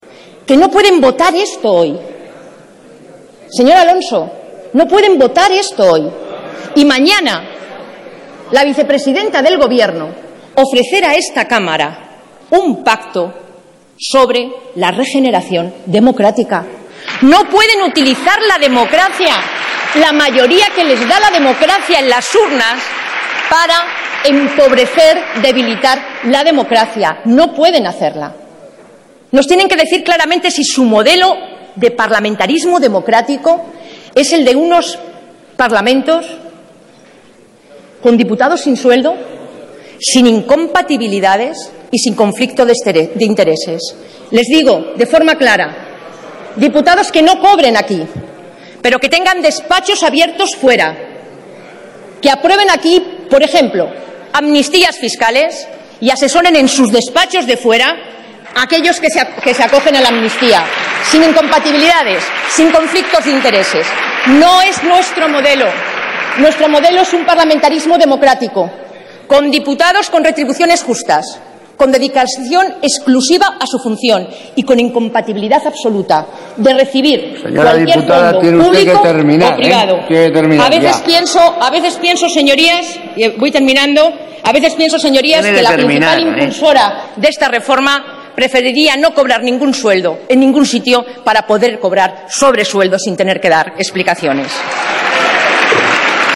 Fragmento de la intervención de Soraya Rodríguez durante el debate en el Congreso de los Diputados de la reforma del estatuto de autonomía de Castilla-La Mancha 19/11/2013